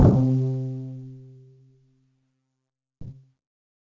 Bass WaveNet
There is a slight built-in distortion due to the compression of the 8-bit mu-law encoding.
While the WaveNet autoencoder adds more harmonics to the original timbre, it follows the fundamental frequency up and down two octaves.
Bass-WaveNet.mp3